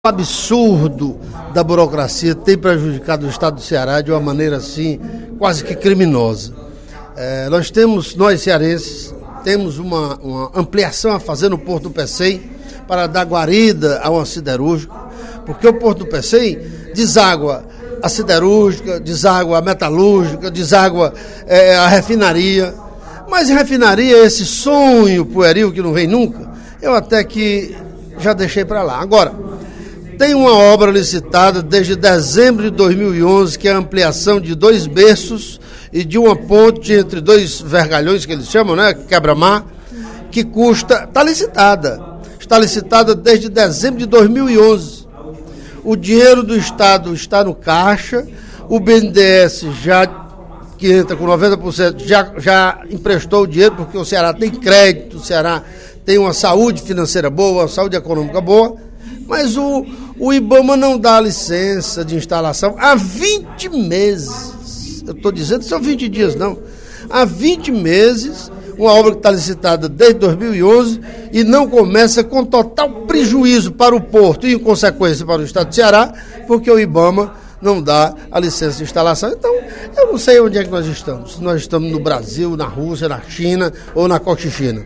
O deputado Carlomano Marques (PMDB) retornou à tribuna, no primeiro expediente da sessão desta quarta-feira (16/10), para tratar, mais uma vez, da situação da insegurança nos municípios do interior do Ceará.
Em aparte, o deputado João Jaime (DEM) afirmou que a questão é preocupante, já que o Porto do Pecém é um dos maiores vetores do desenvolvimento do Estado.